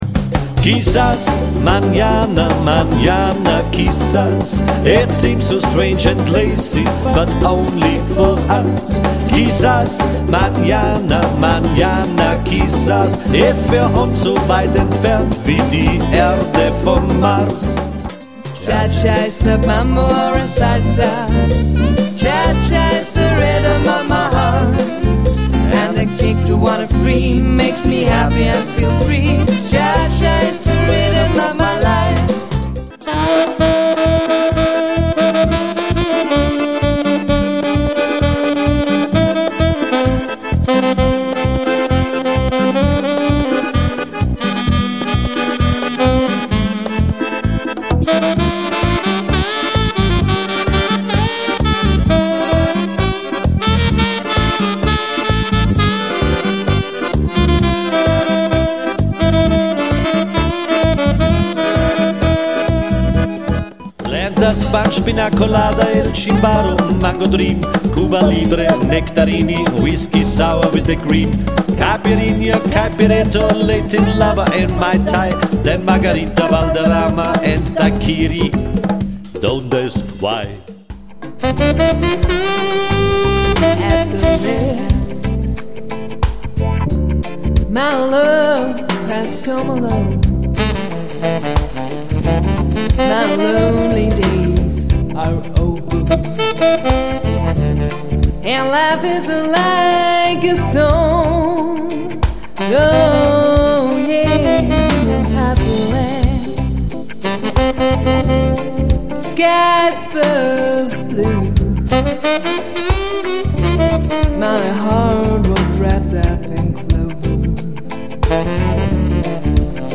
Jazz- und Popgeschichte.
mit Sax- und Querflötenriffs aufpoliert.
und das rhytmische Feeling aus Südamerika